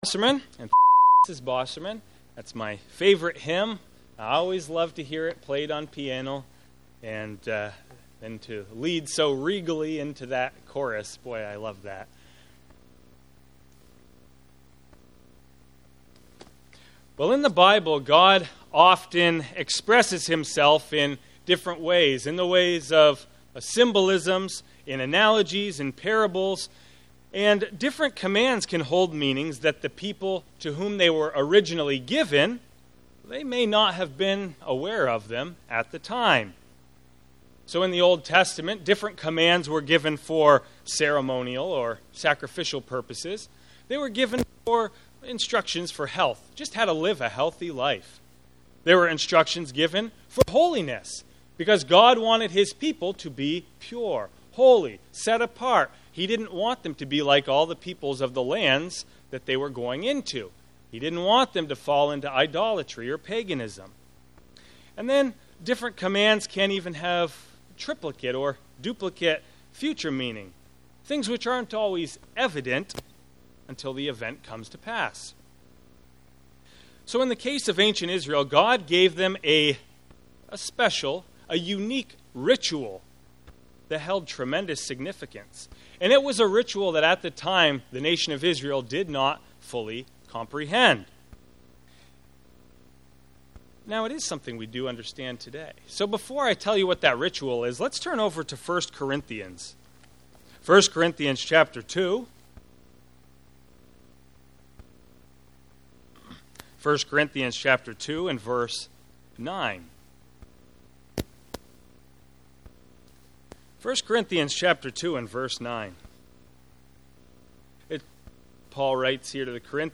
The symbolism of the ceremonies recorded in Leviticus chapter 16 point to critical parts of God's plan. Certain things were done by the high priest only on the day of Atonement. This sermon explores what Aaron did with the two goats and the incredible part they picture in the grand plan of God.